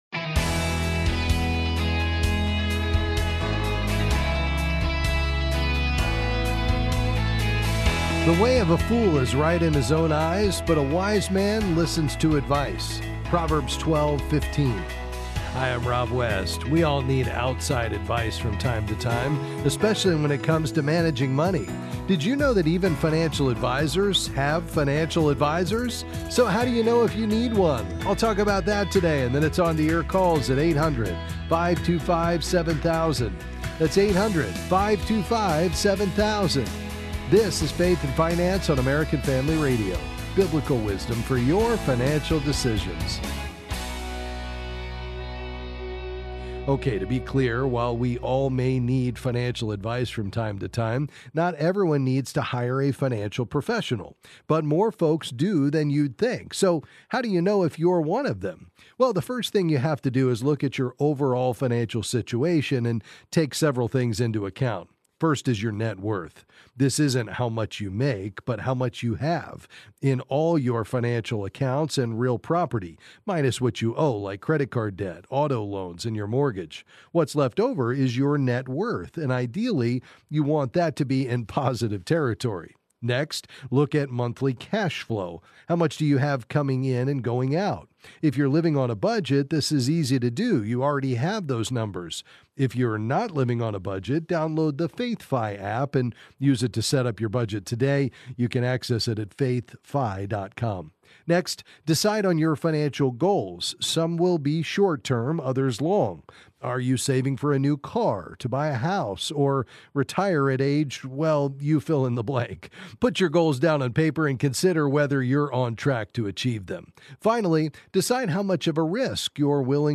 Then he’ll take your calls and answer the financial question on your mind.